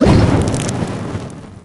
barkeep_throw_ulti_01.ogg